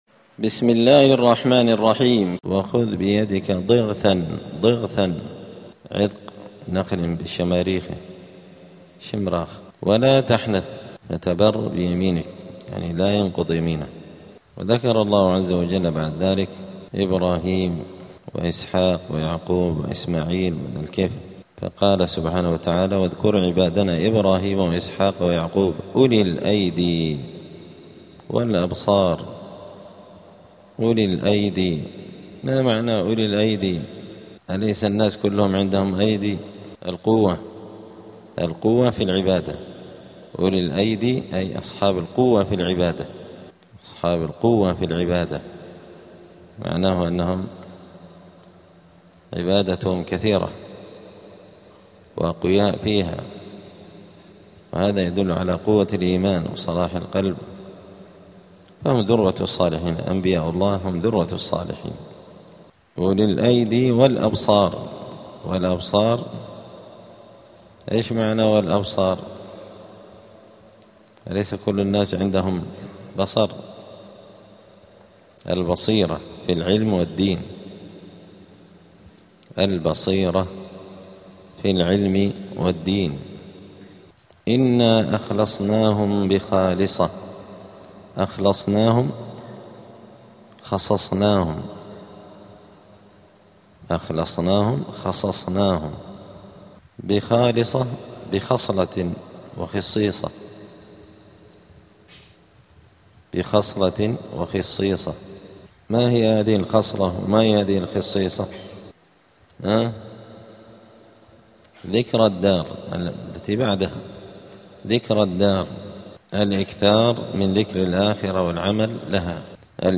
الأحد 4 جمادى الأولى 1447 هــــ | الدروس، دروس القران وعلومة، زبدة الأقوال في غريب كلام المتعال | شارك بتعليقك | 6 المشاهدات